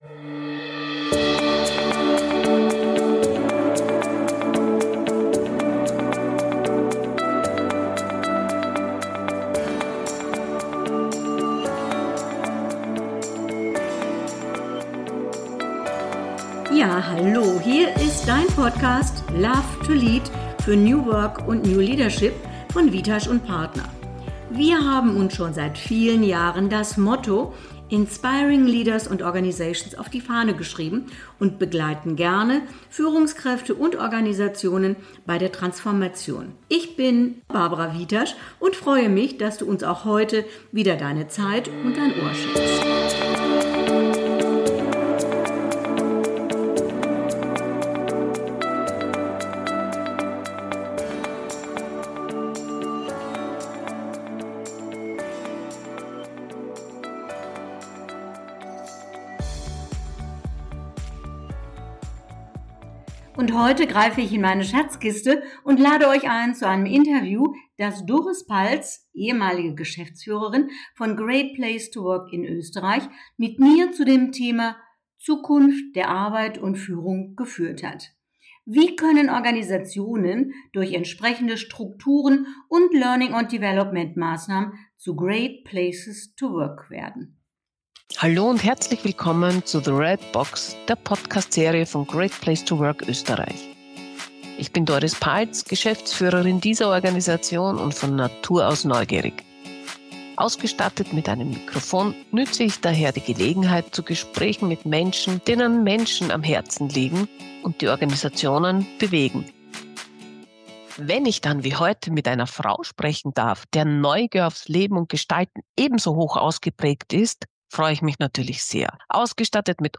Zwei Frauen, verbunden durch Working Out Loud, vereint im Wunsch, die Arbeitswelt besser zu machen.